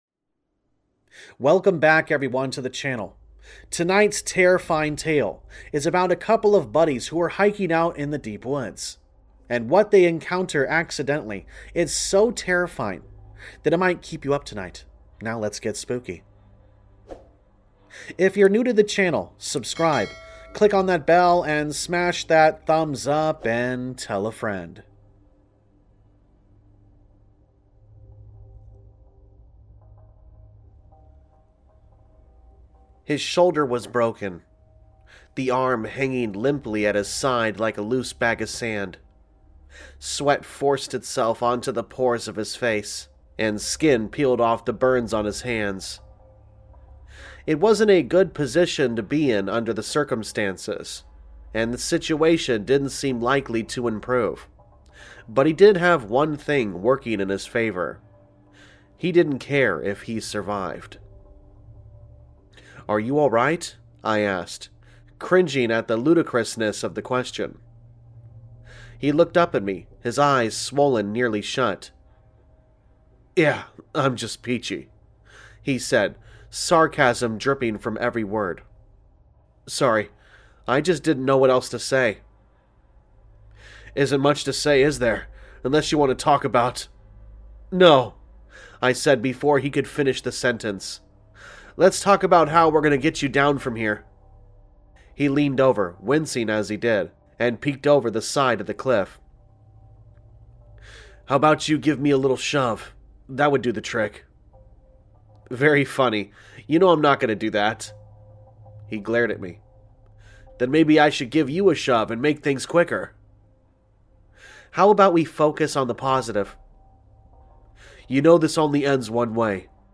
Sound Effects Credits